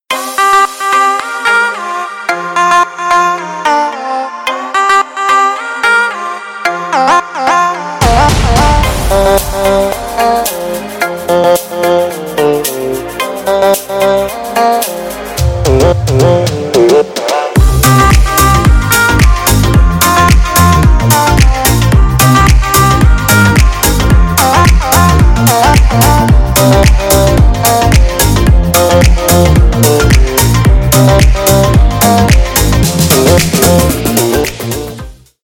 • Качество: 320, Stereo
поп
ритмичные
dance
Electronic
без слов